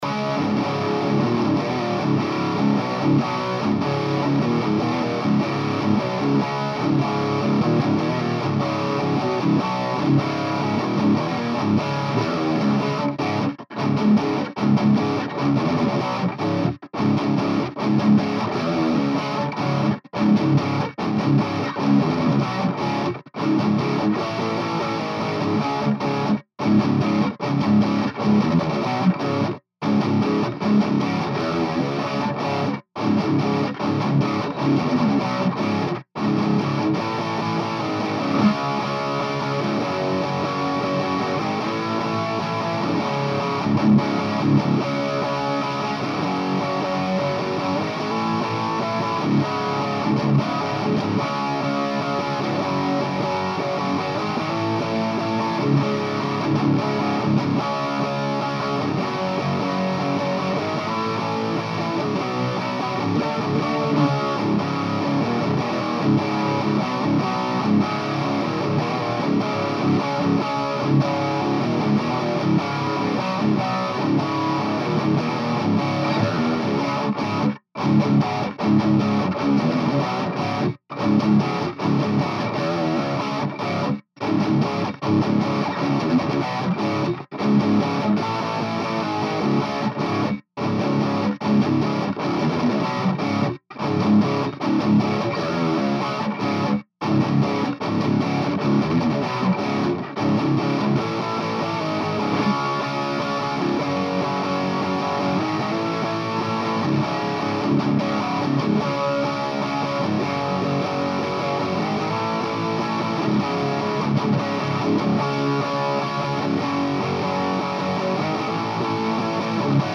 EG5, VHT 2/90/2, and Grendel Dead Room Iso Cab w/ WHS V30
Here is try #2 w/ the grendel. Same song, better mic placement, and the addition of the Sennheiser e906 . Its a much more refined sound, and the tone I was really looking for. The prominent mic in the mix is the 57 though. The e906 is mixed in for flavor and punch of the low end.